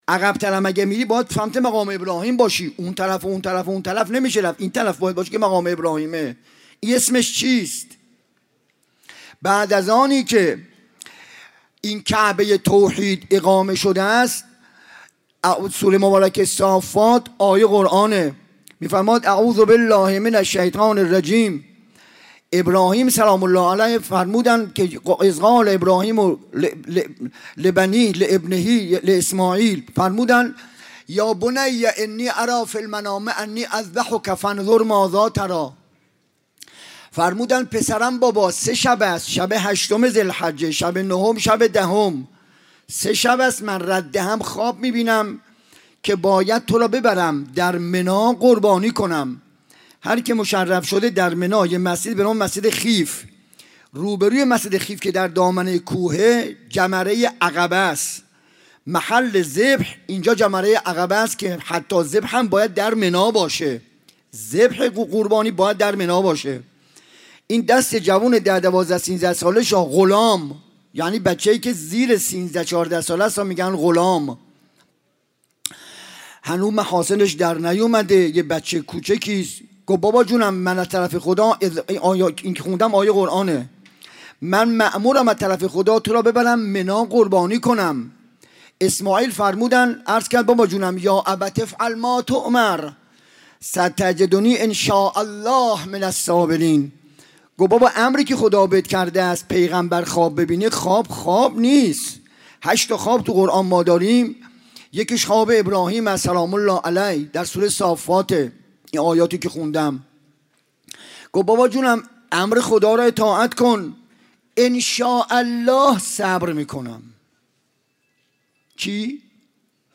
سخنراني